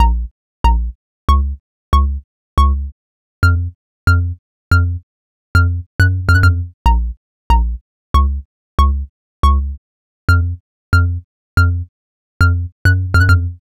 Exodus - Arpeggiated Pluck Bass.wav